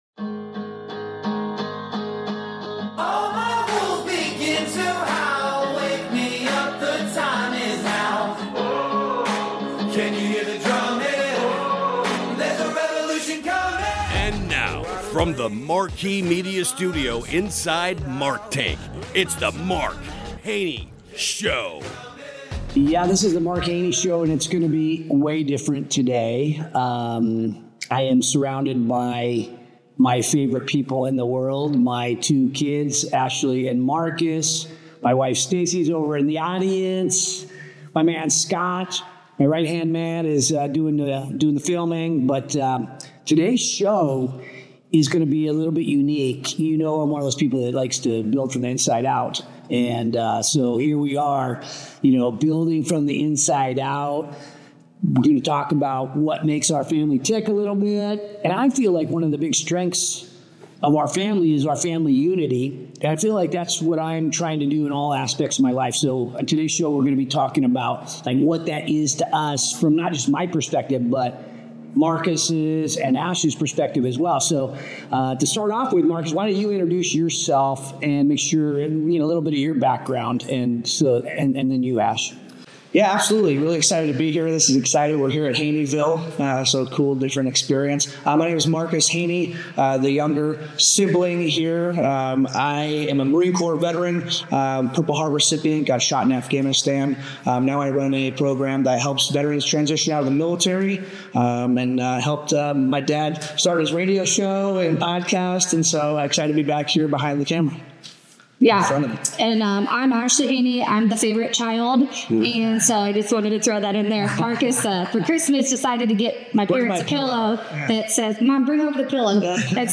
It's an honest, unscripted look at how we strive to live with purpose, build meaningful connections, and prepare the next generation for success.